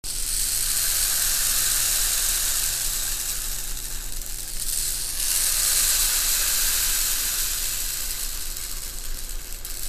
LP Rainstick - Crystal 18" (LP456A)
The LP Crystal Rainstick is made of high strength transparent plastic which makes it visually exciting to watch. The steel fill and plastic construction produces a louder sound that cuts through amplified performances.